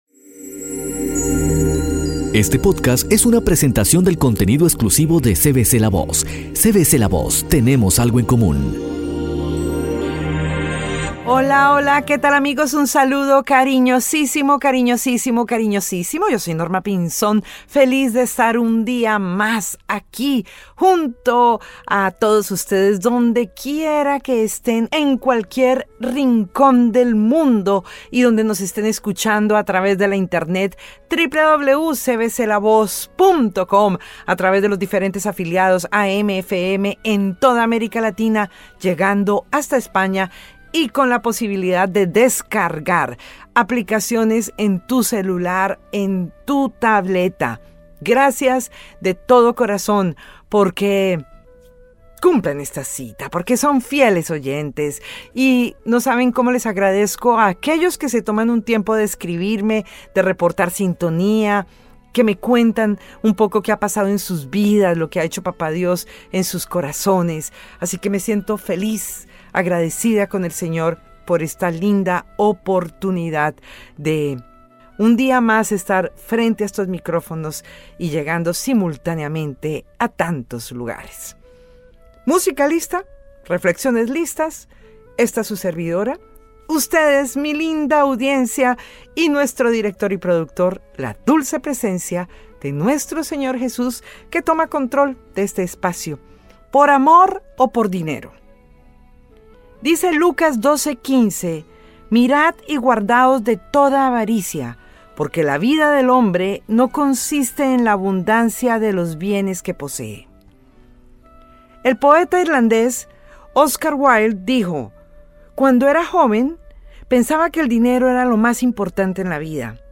Naveguemos por las páginas de la Biblia con la dulce voz de